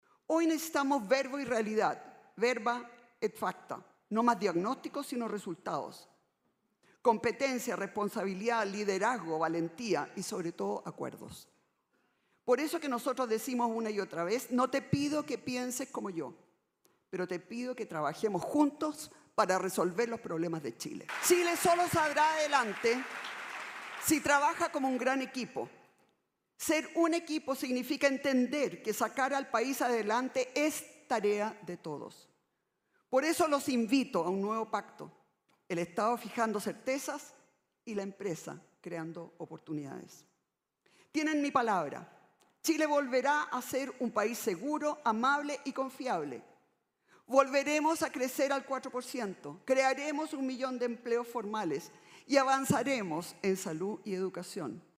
A continuación, la candidata de Chile Vamos, Evelyn Matthei, expuso que el país necesita acuerdos y comprometió un “nuevo pacto: el Estado fijando certezas y la empresa creando oportunidades”.